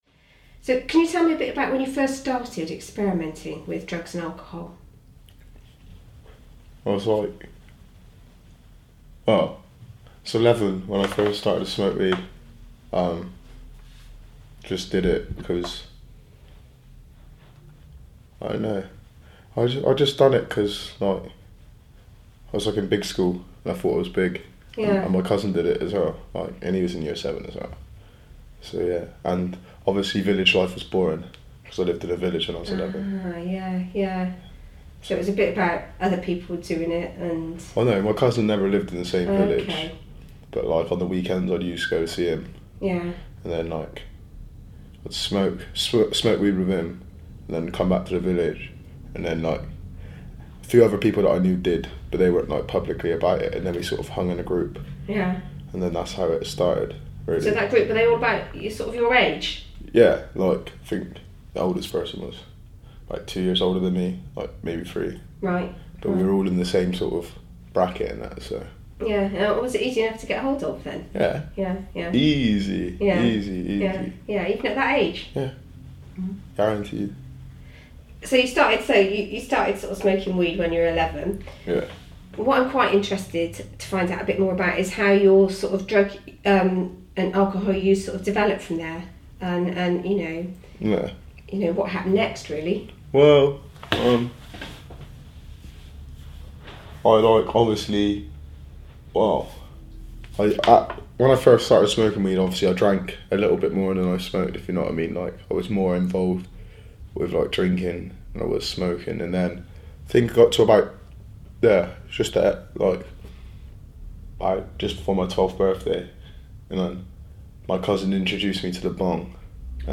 Year 11 Project 28 taped interviews